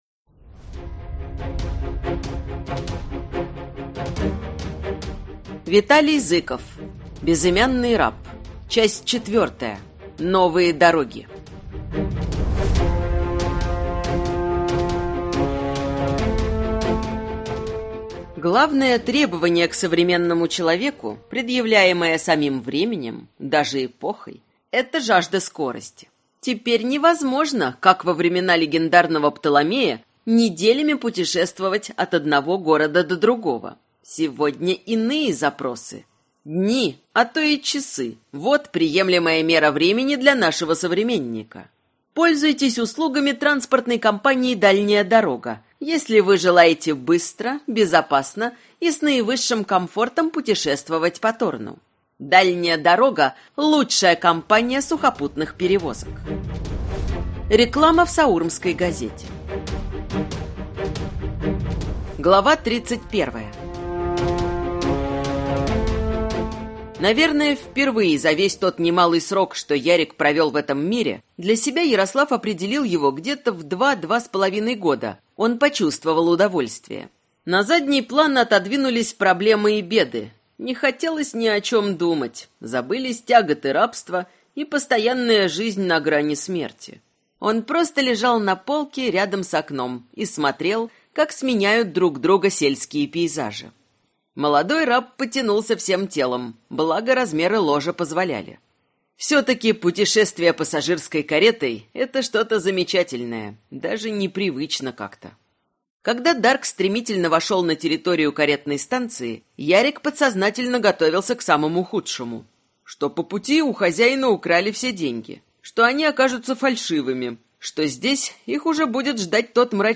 Аудиокнига Безымянный раб. Часть 4-я | Библиотека аудиокниг